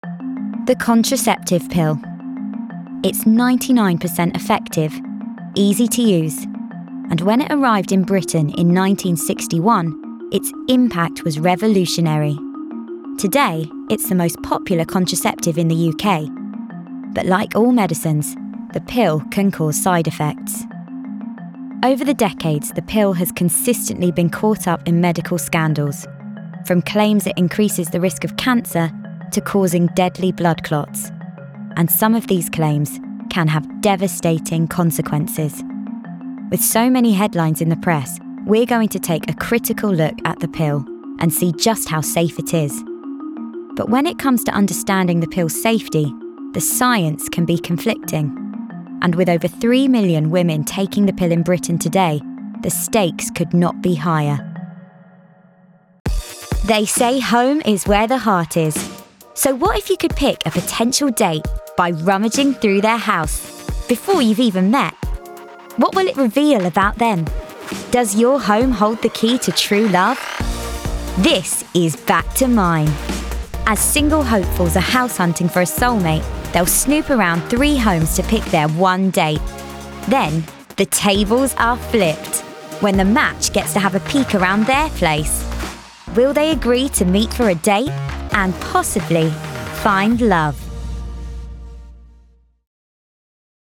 Narration Showreel
Female
London
Neutral British
Confident
Cool
Down To Earth
Youthful